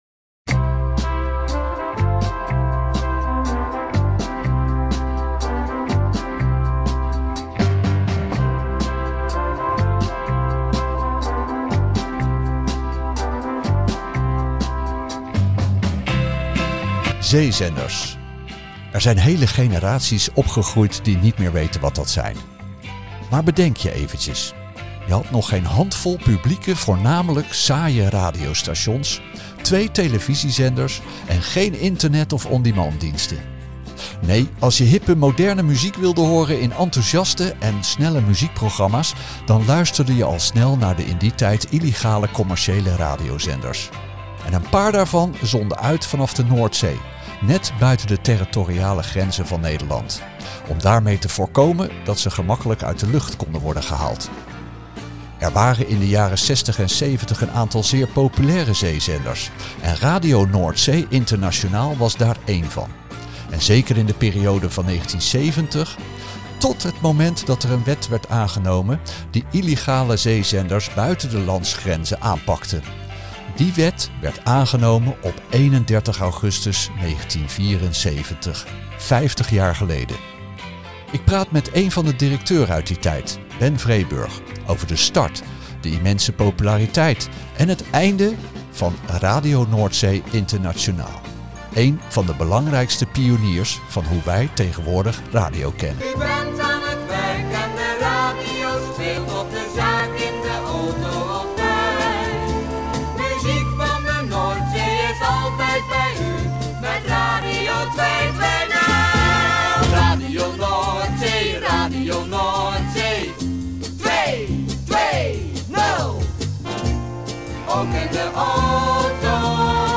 De gehoorde originele fragmenten geven dit alles prachtig weer!